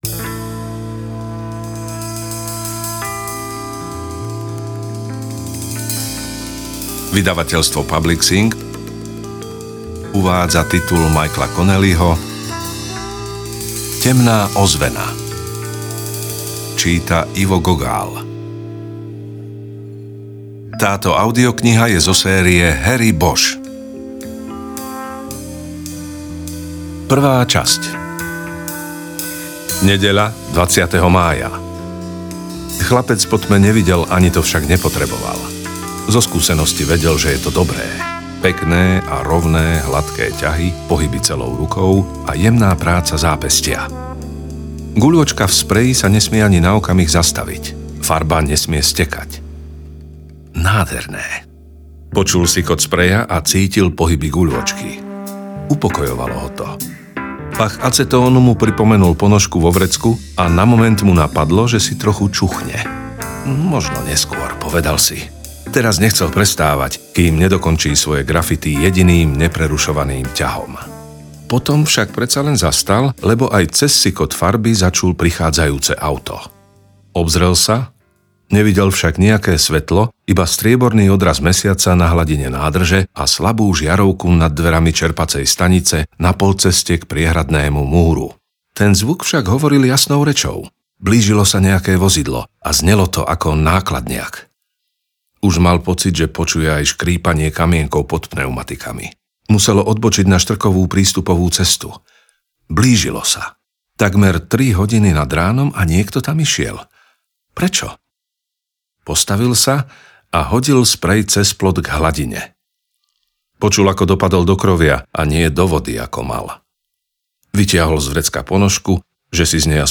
Temná ozvena audiokniha
Ukázka z knihy
temna-ozvena-audiokniha